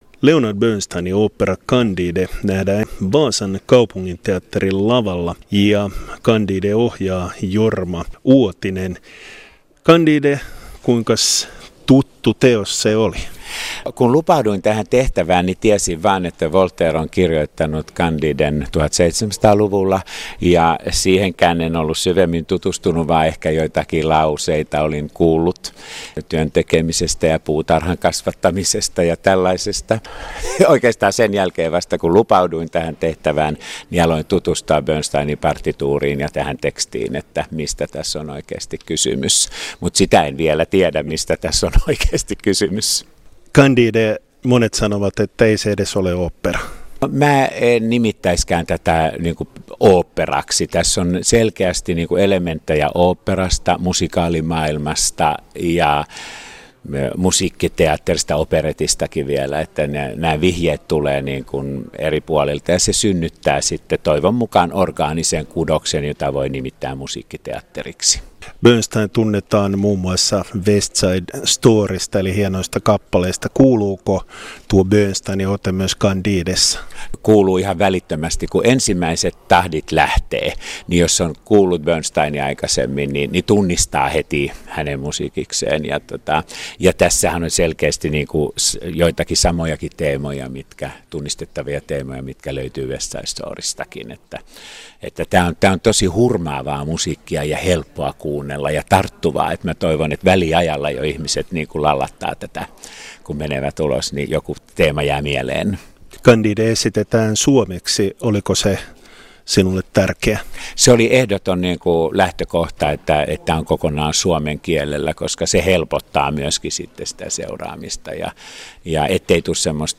haastattelussa on oopperan tekijöitä